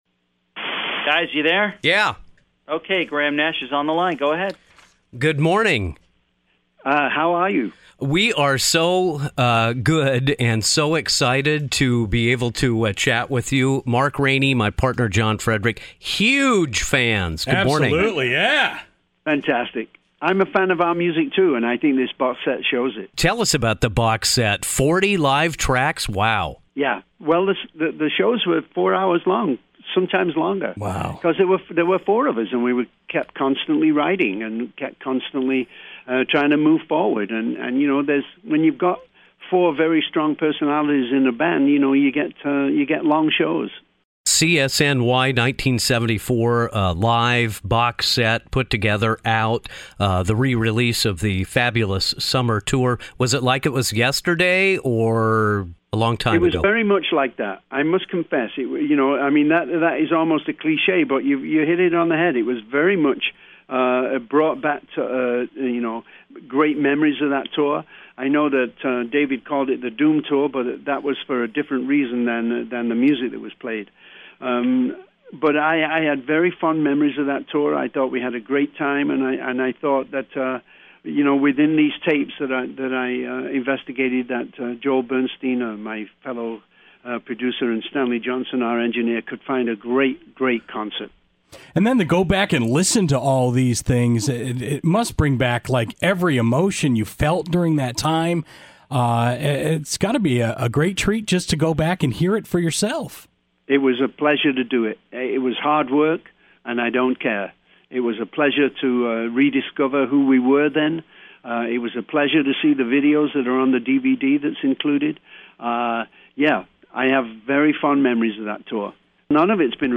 Throwback Interview - Graham Nash 08-04-14